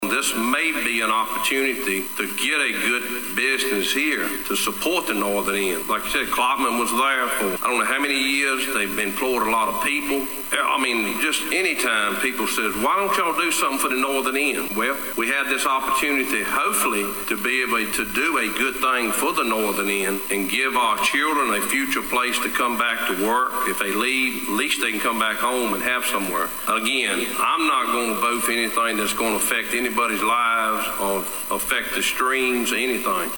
Callands-Gretna Supervisor Darrell Dalton says the rezoning is a good opportunity for the northern end of the county.